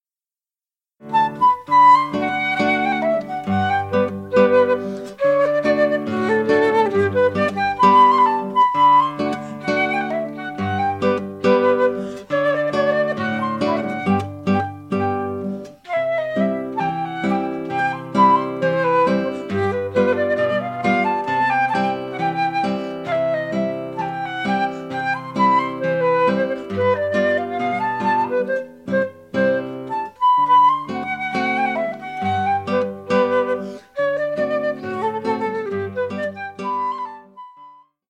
Flute and Guitar